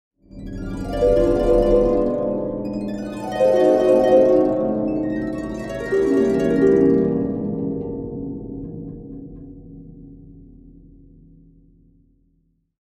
Mystical-ethereal-harp-arpeggio-sound-effect.mp3